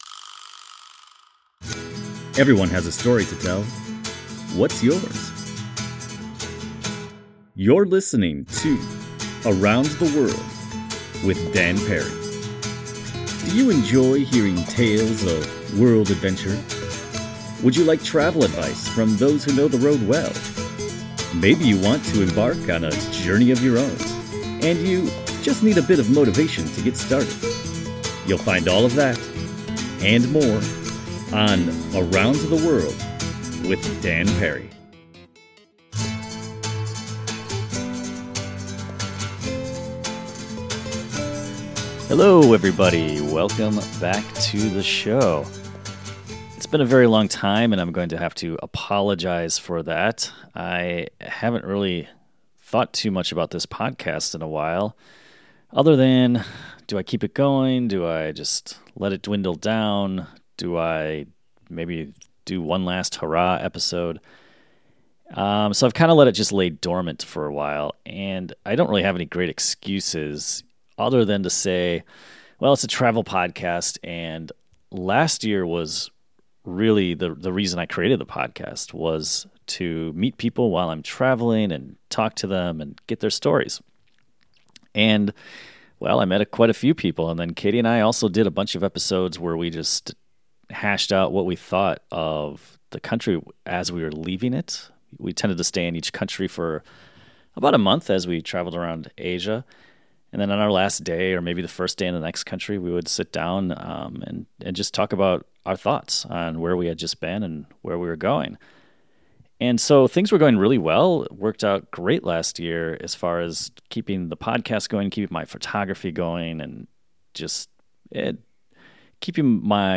We chatted about how he got here and what lies on the road ahead for him.